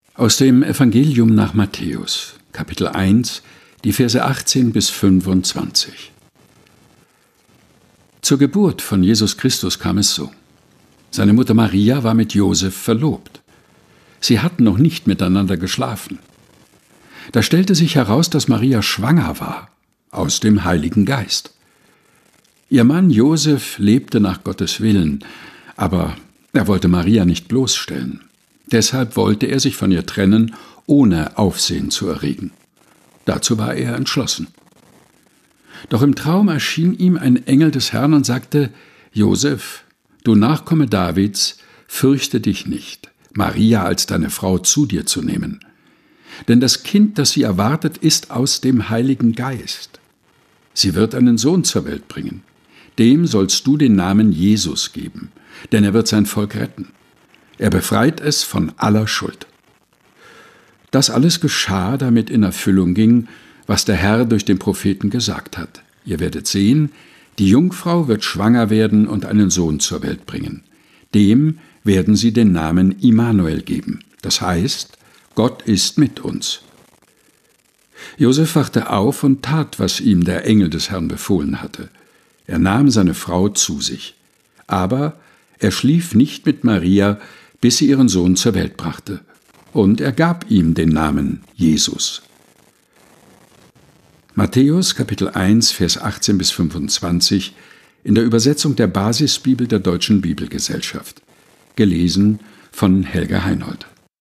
ausgesucht und im Dachkammerstudio vorgelesen